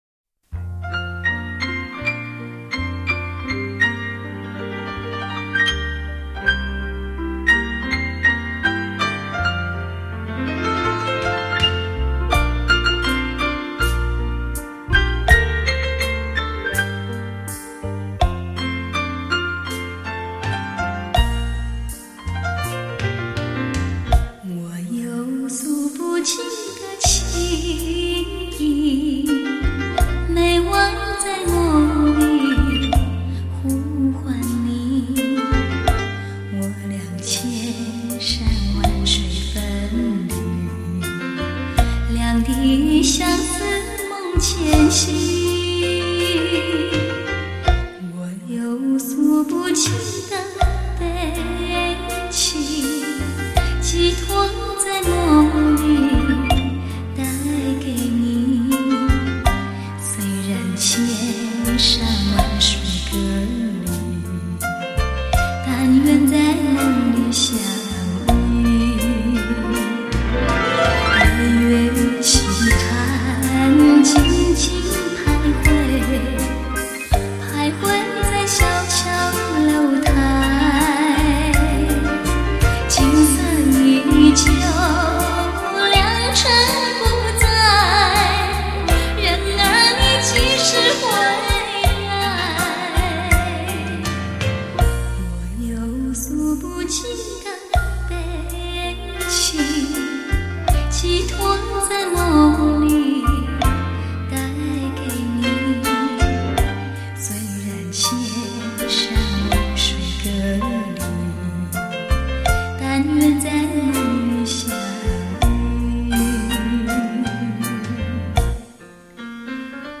甜歌伴舞
华尔兹